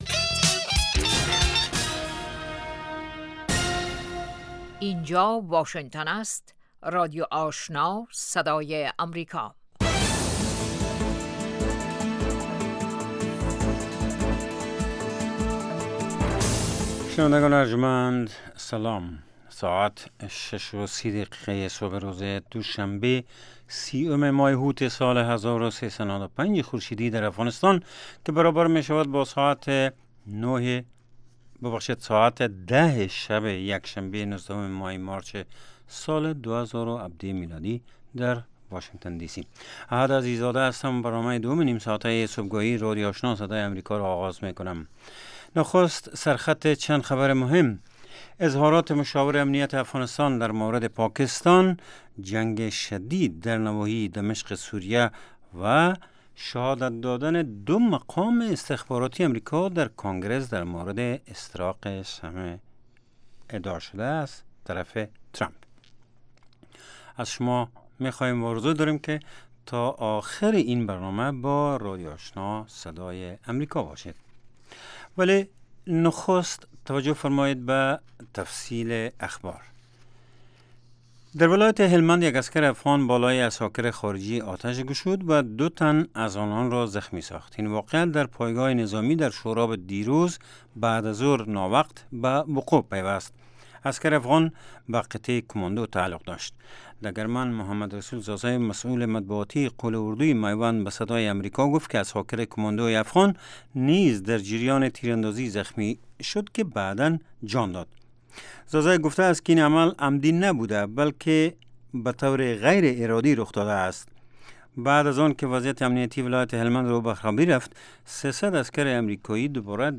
دومین برنامه خبری صبح